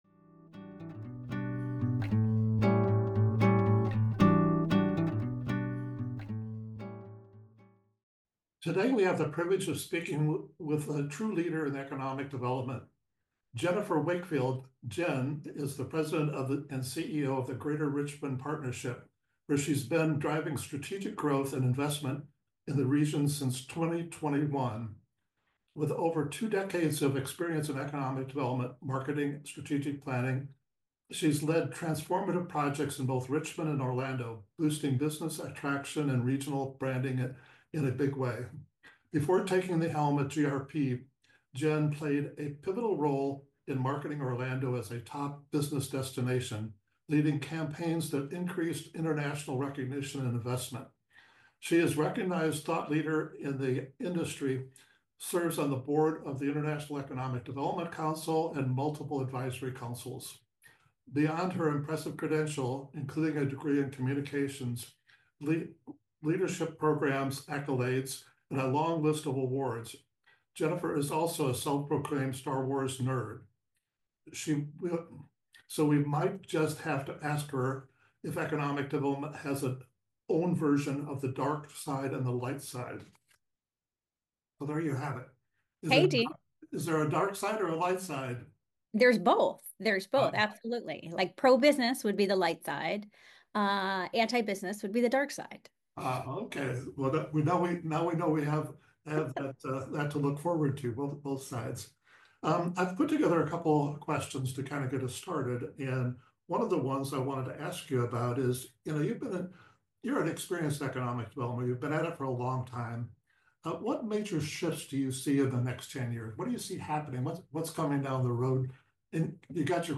Interviews with Thought Leaders in Economic Development The Whittaker Report Podcast Episode 28